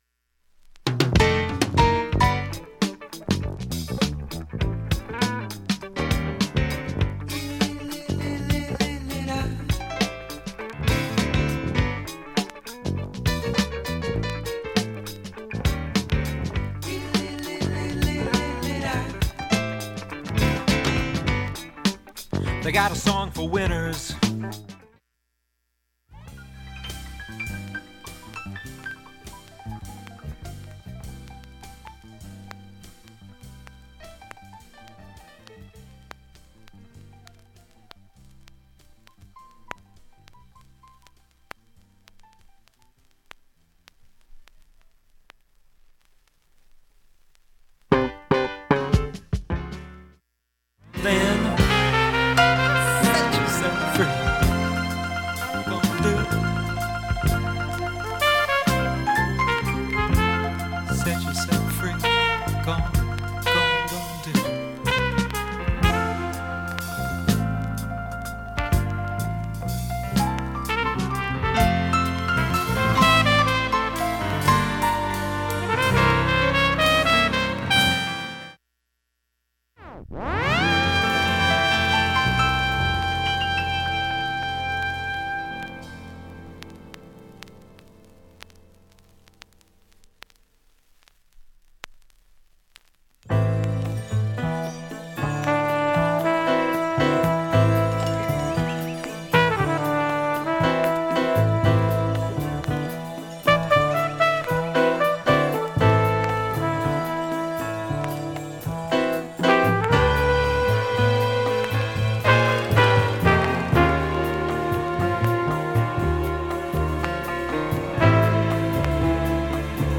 基本クリアな音質良好全曲試聴済み。
２分２０秒の間に７０回プツ出ますが
プツプツですが音は小さめです）
ジャズとAORの両シーンから人気の6作目